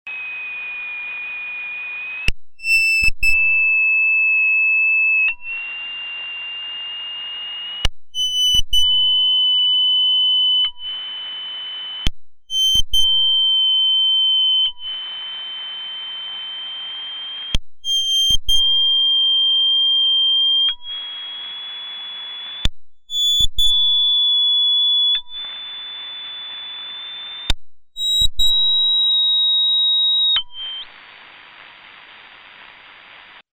В начале каждого слова (на громком операторе) слышен скрежет со щелчком от ограничения выбросов.